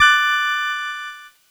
Cheese Chord 20-D4.wav